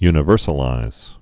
(ynə-vûrsə-līz)